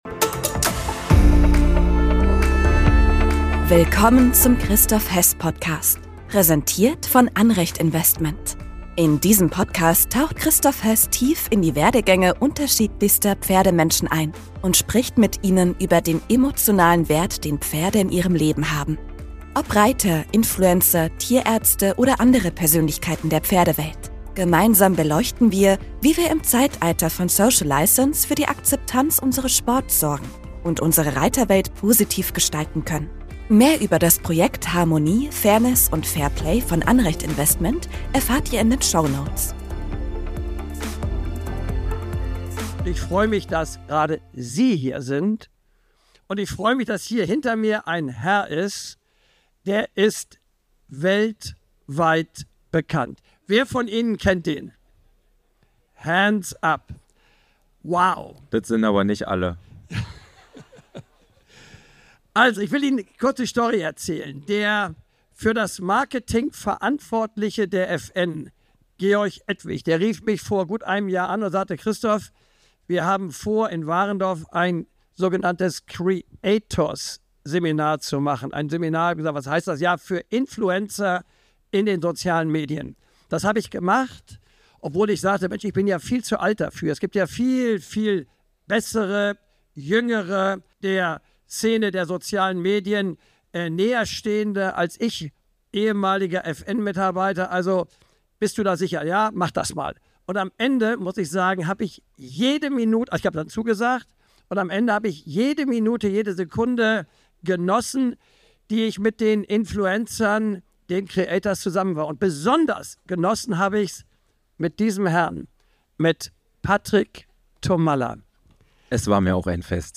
Diese Folge wurde als Live-Podcast bei den Bundeschampionaten in Warendorf im September 2024 aufgezeichnet.